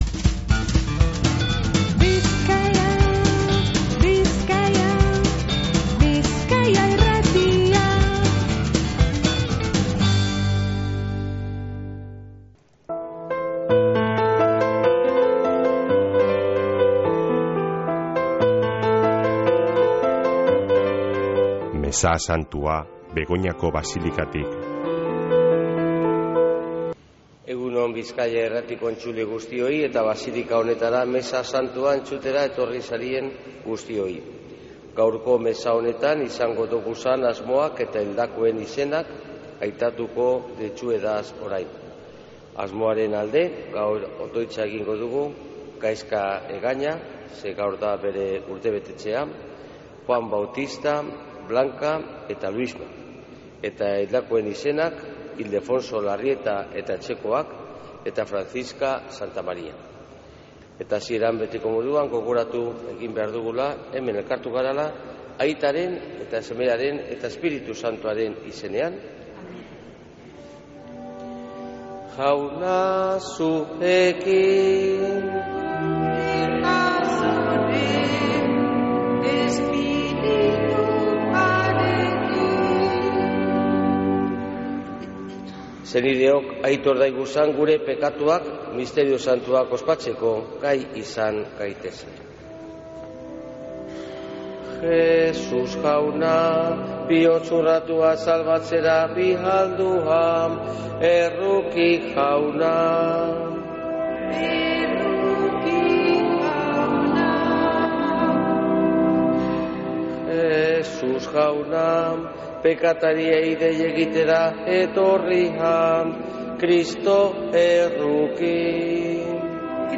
Mezea Begoñako Basilikatik | Bizkaia Irratia
Mezea (25-02-12)